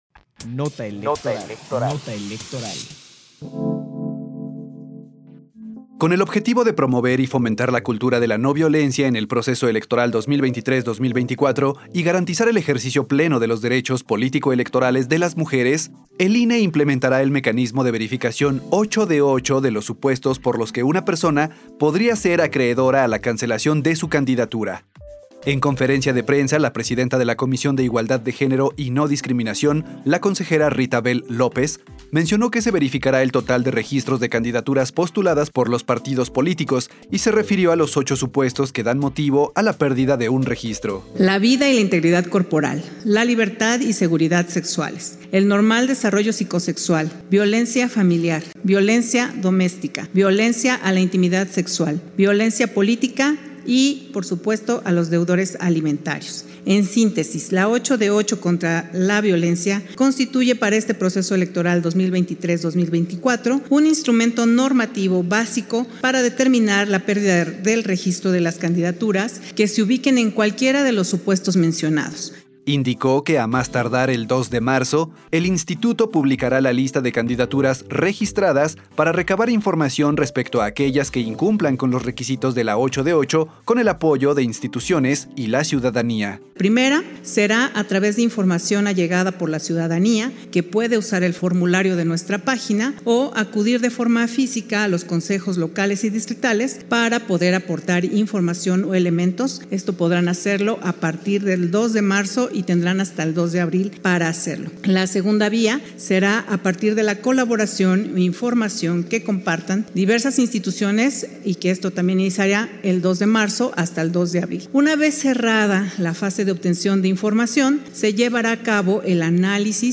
Nota de audio sobre la implementación del mecanismo de verificación 8 de 8 contra la violencia de género